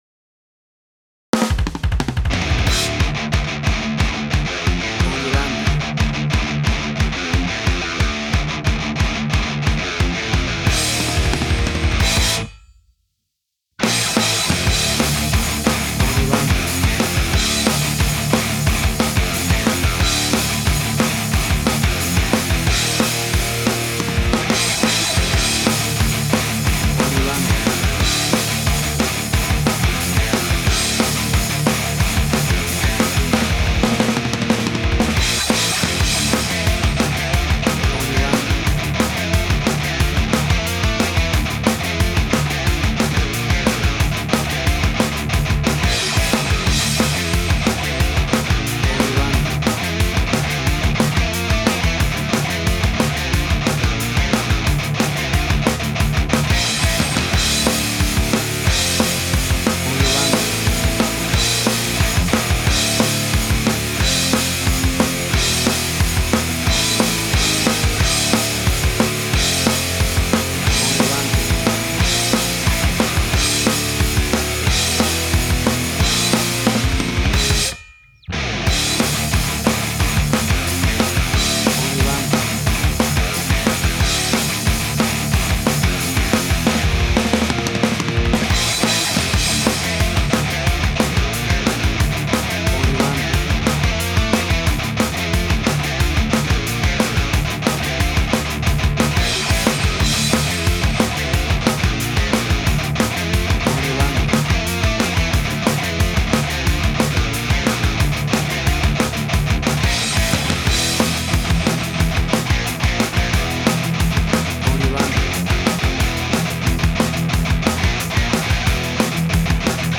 Tempo (BPM): 180